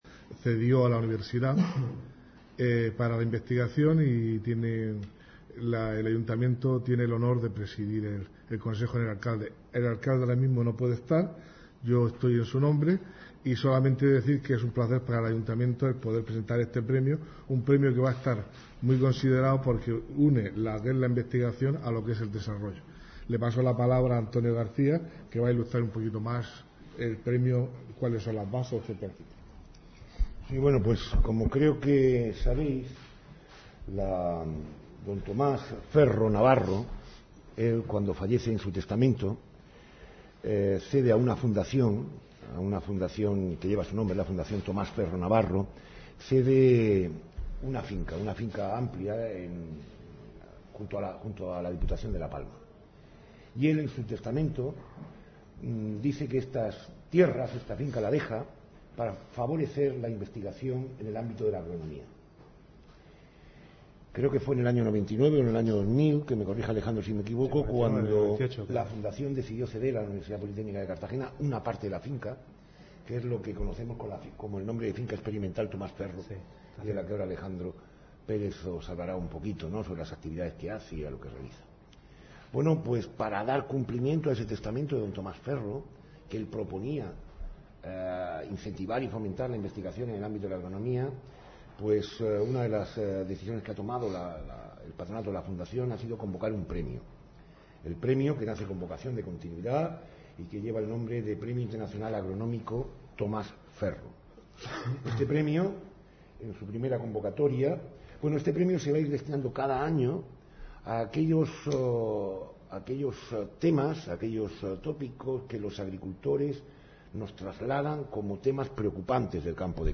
Esta mañana, el concejal del área de Calidad de Vida, Francisco Calderón (MC), ha presentado, en la Sala de Prensa del Palacio Consistorial, el I Premio Internacional Agronómico Tomás Ferro.